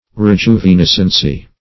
Rejuvenescency \Re*ju`ve*nes"cen*cy\ (-sen-s?), n.
rejuvenescency.mp3